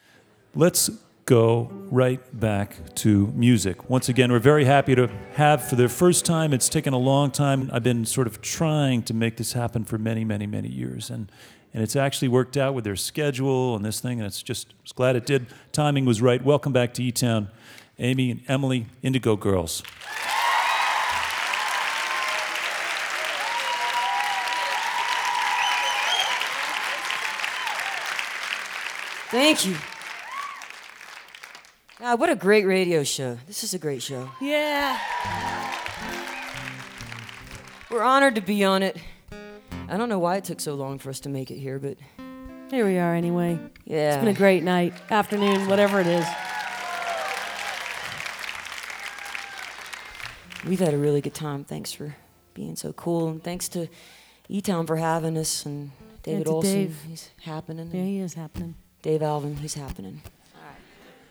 06. encore (1:01)